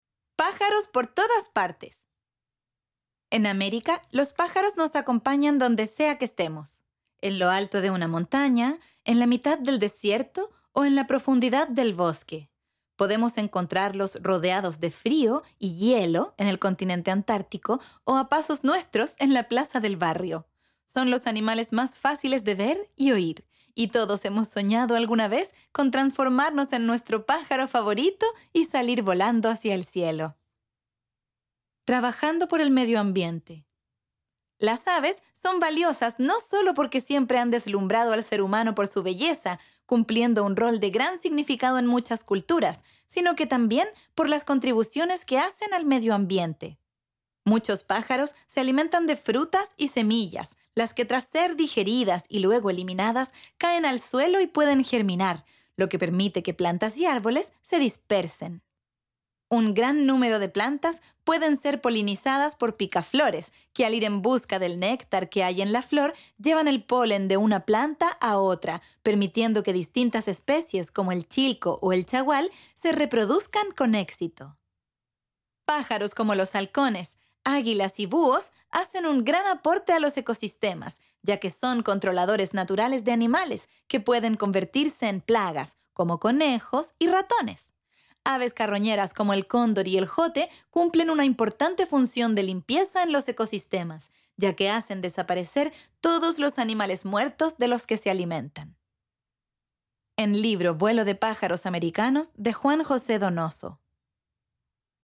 Audiocuentos